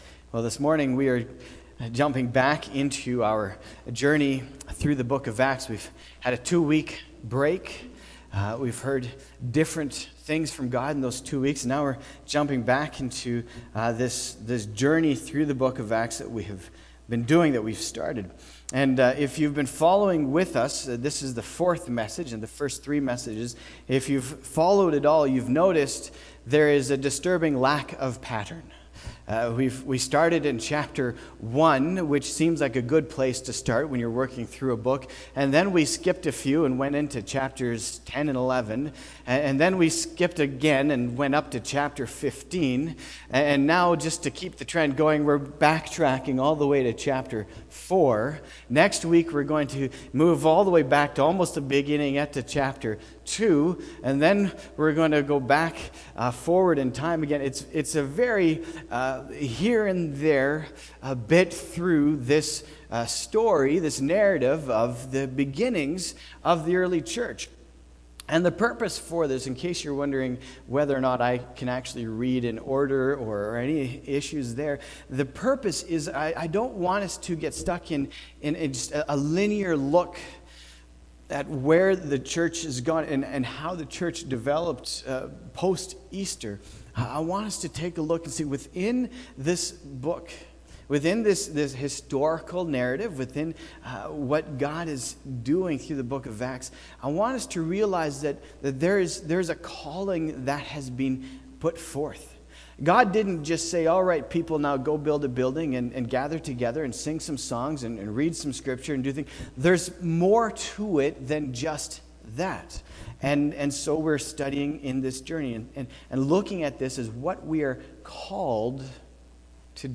sermon-june-2-2019.mp3